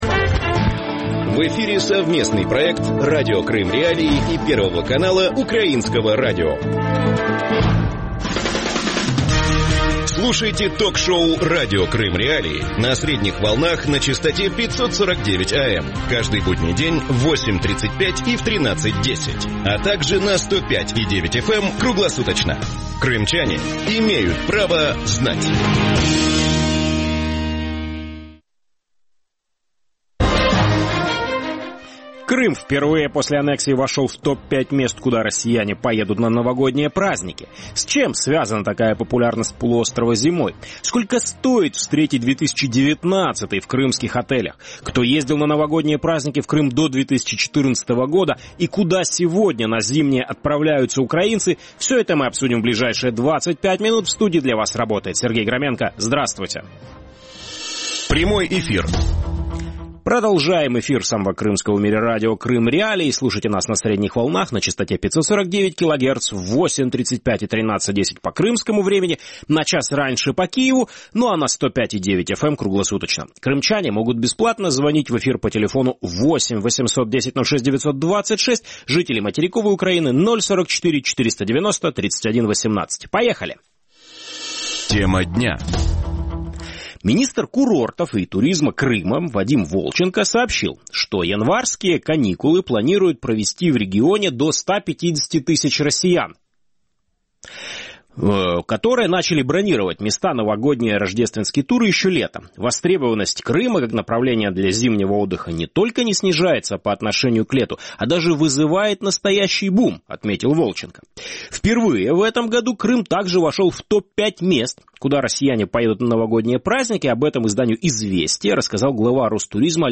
будет говорить с гостями эфира